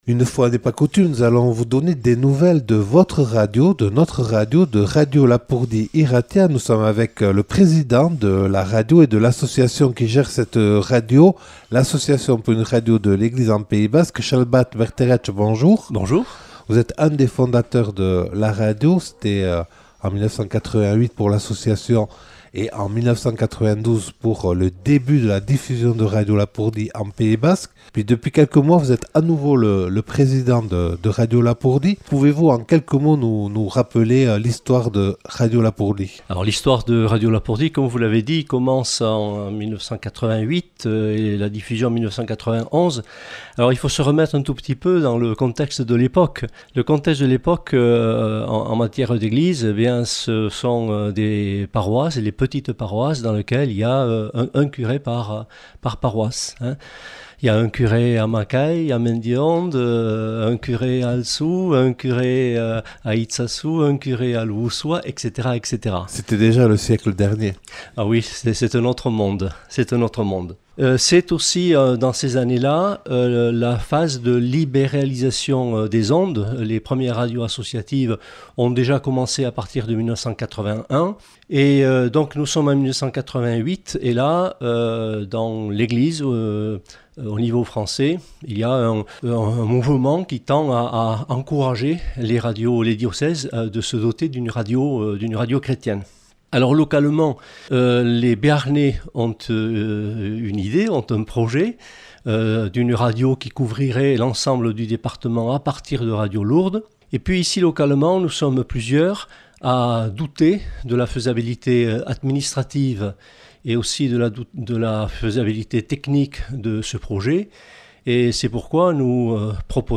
Accueil \ Emissions \ Infos \ Interviews et reportages \ Pour que vive Radio Lapurdi Irratia, je fais un don !